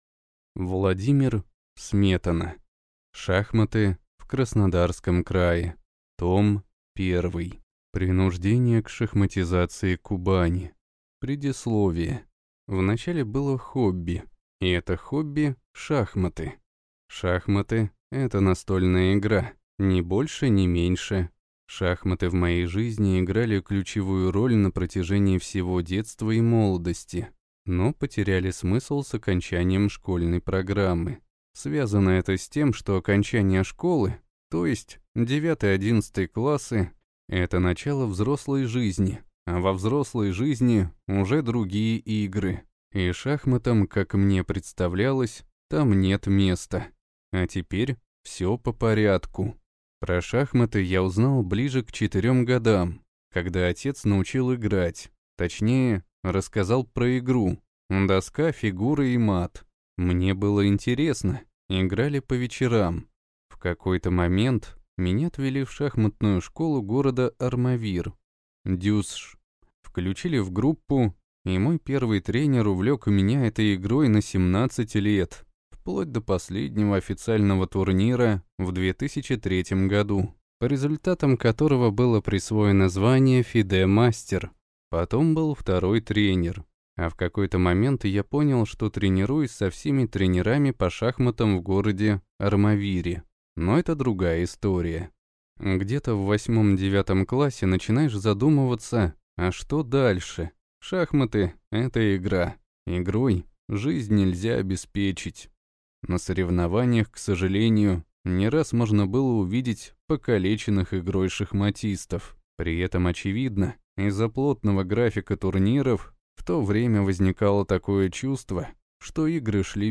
Аудиокнига Шахматы в Краснодарском крае. Том 1: Принуждение к шахматизации Кубани | Библиотека аудиокниг